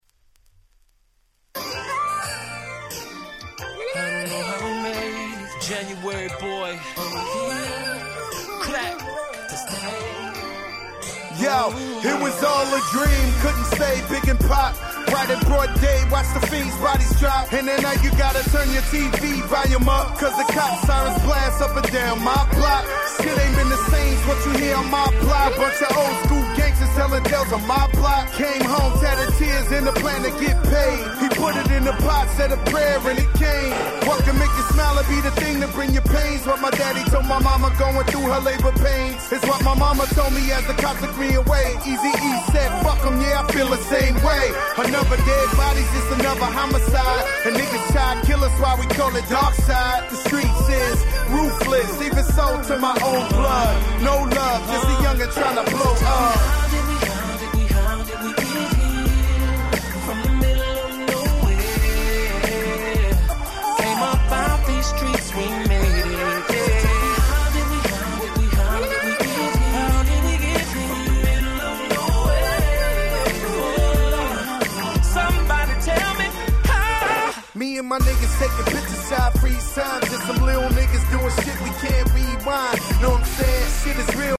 10' Nice Hip Hop !!